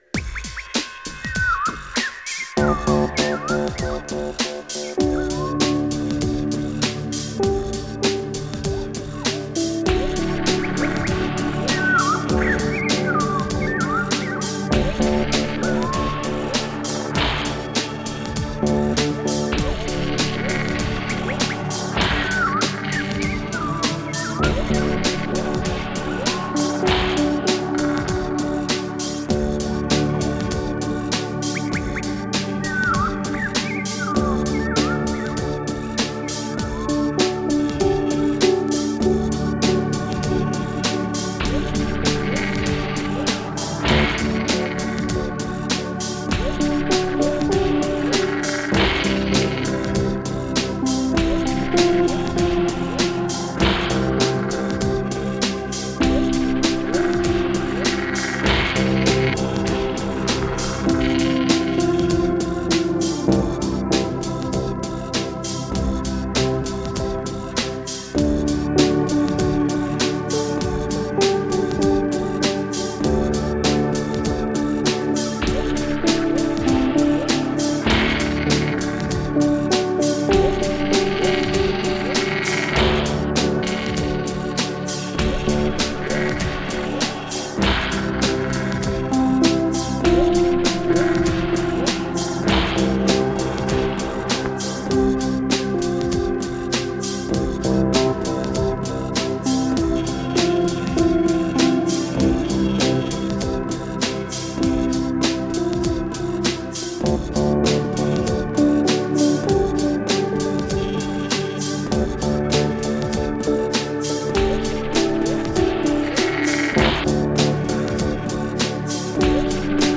Tempo: 100 bpm / Datum: 29.01.2018